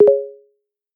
dingdong.mp3